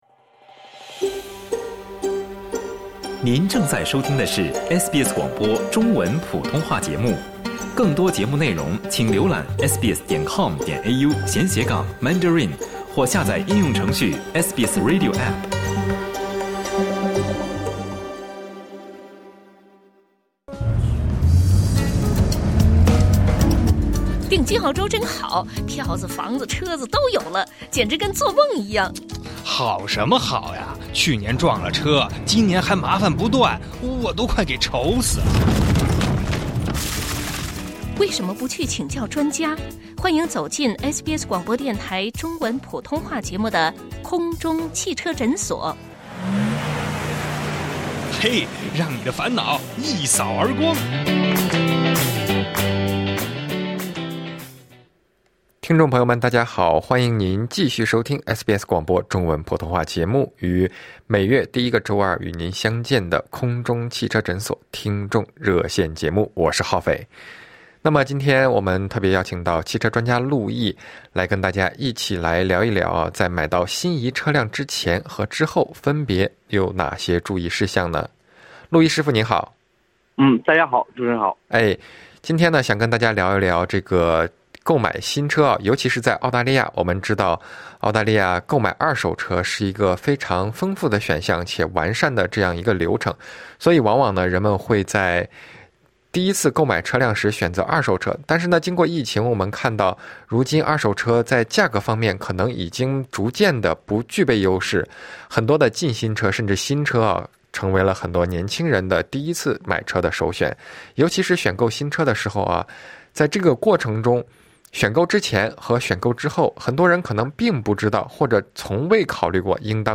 爱车保养、故障判断、解答车辆养护疑问，欢迎您参与SBS普通话节目《空中汽车诊所》热线节目，咨询汽车问题。